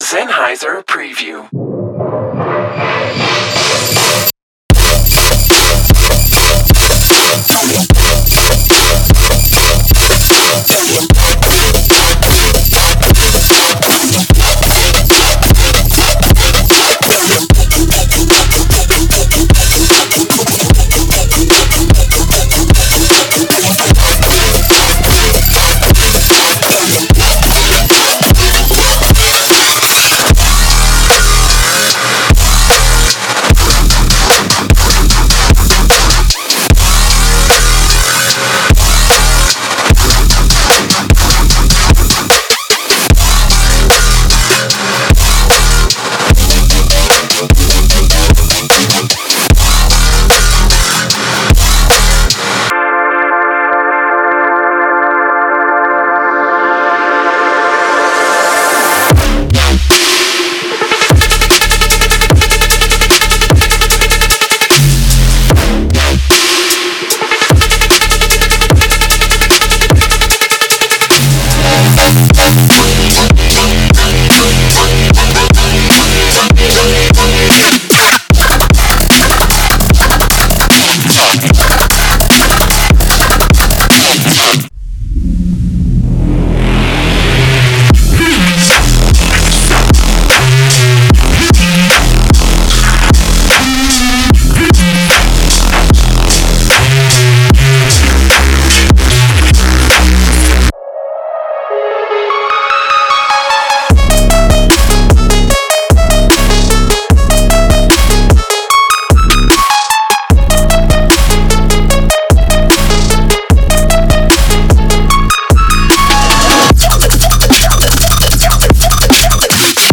DubstepTrap
故意使黑暗和沉重的“陷阱进化”深入研究了陷阱音乐和杜贝斯特音乐的发展。
陷阱样品包中的所有物品绝对是原始的，扭曲的，震荡的和扬声器晃动的。